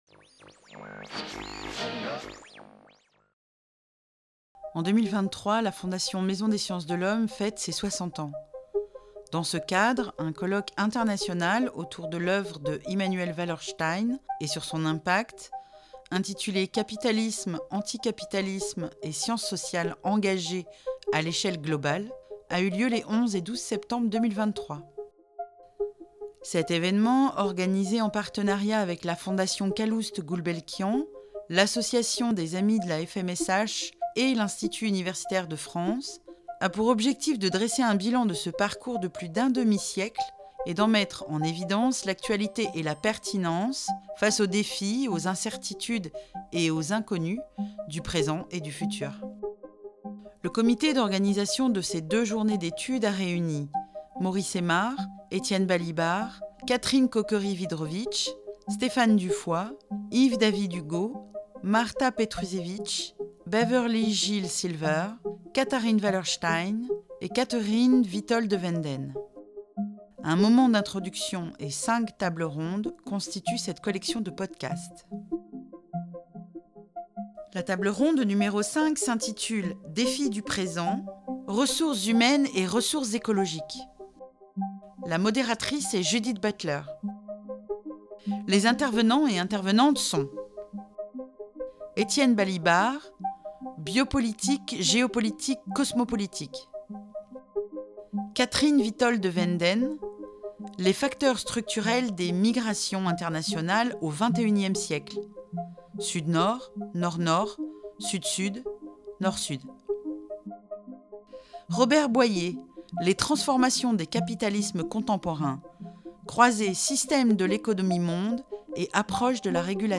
Conférence
Modératrice : Judith Butler